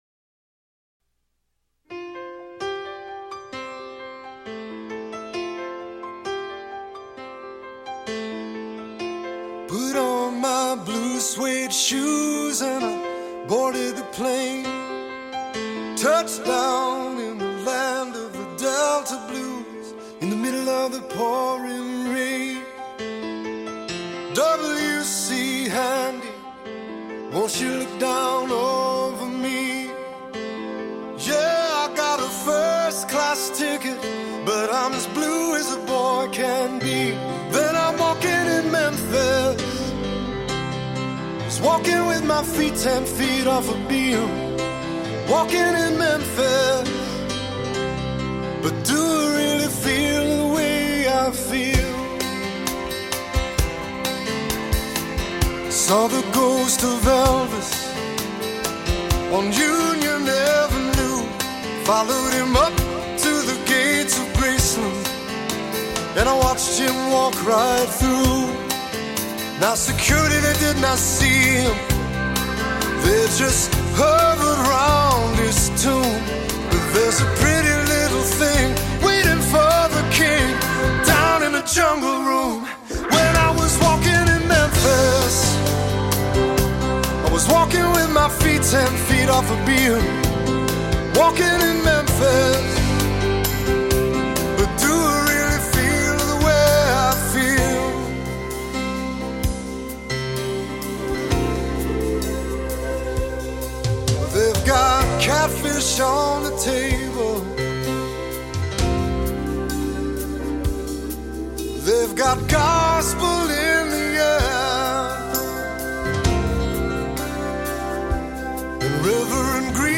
Nice parody.